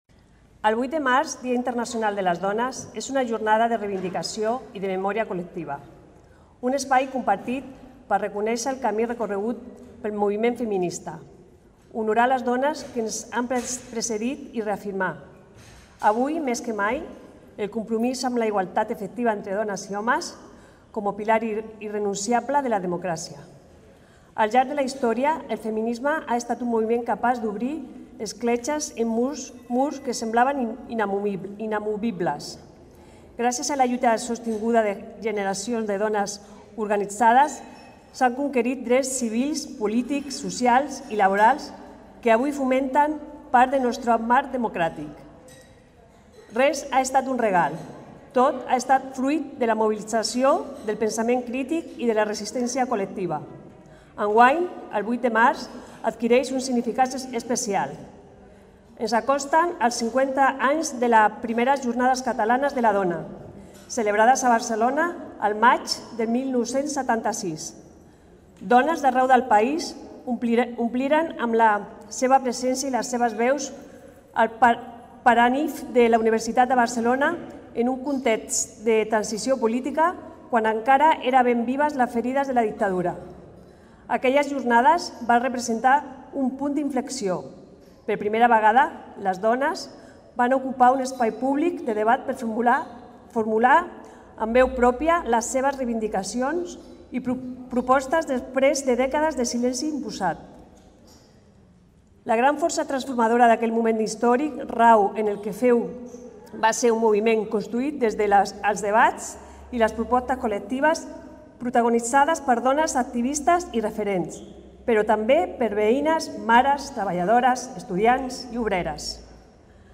Martorell ha commemorat aquest diumenge el Dia Internacional de les Dones (8M) amb l’acte central celebrat a l’interior del Centre Cultural, on la regidora d’Igualtat, Remedios Márquez, ha fet la lectura del manifest institucional consensuat per les administracions catalanes.
Lectura del manifest a càrrec de Remedios Márquez, regidora d'Igualtat
Manifest-institucional-Dia-Internacional-de-les-Dones-8M.mp3